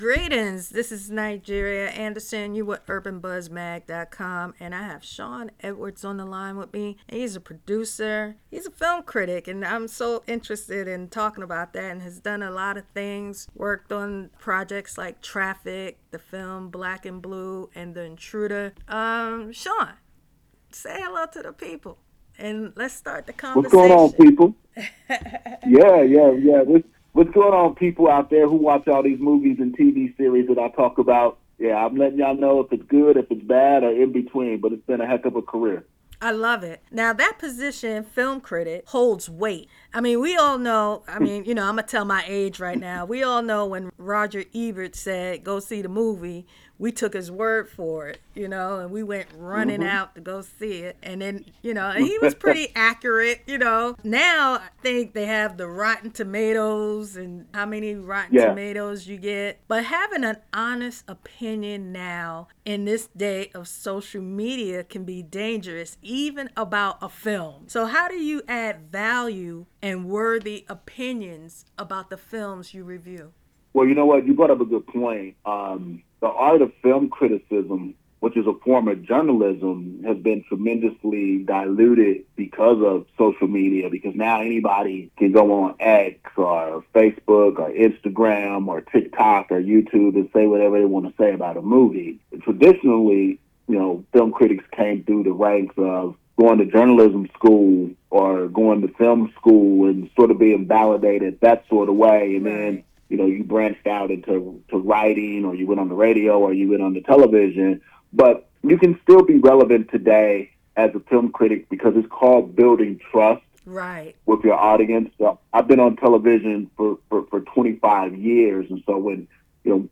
Our interview dives into the realities of the industry and there is a continued fight for representation. Many films have gone overlooked.